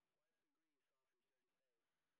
sp05_street_snr20.wav